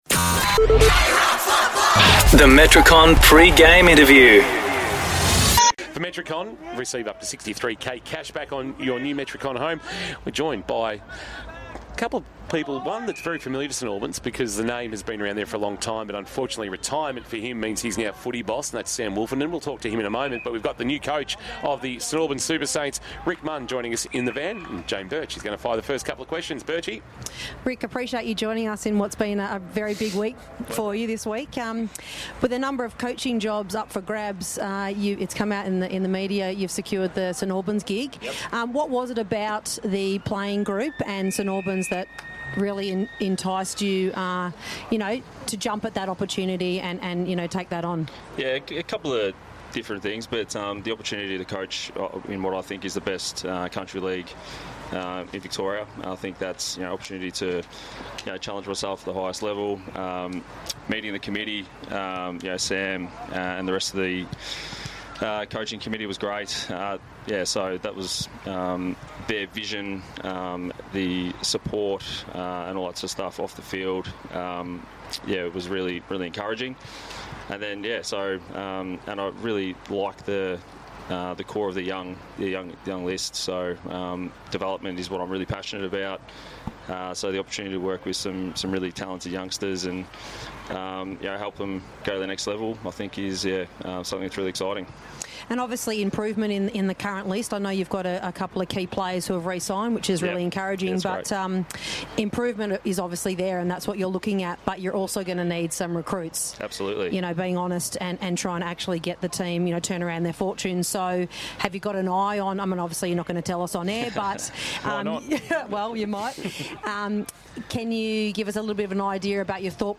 2024 - GFNL - Round 17 - St Mary's vs. St Albans: Pre-match interview